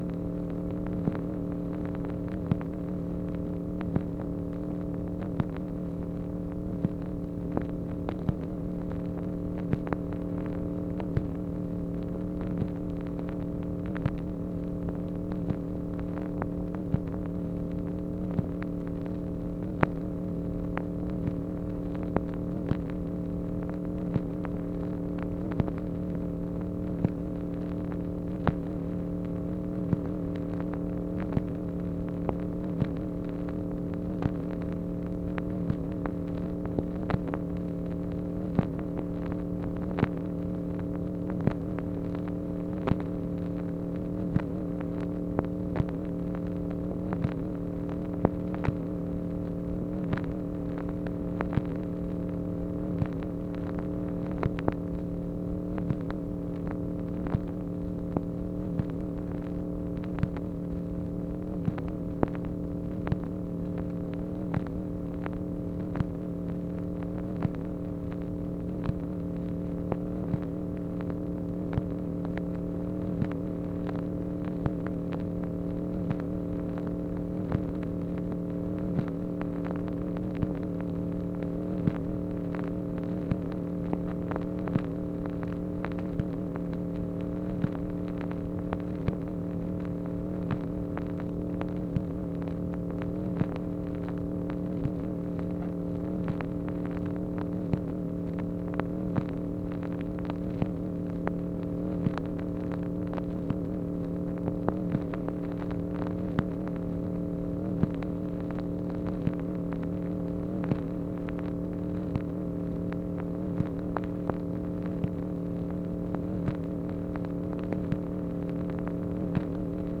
MACHINE NOISE, January 8, 1964
Secret White House Tapes | Lyndon B. Johnson Presidency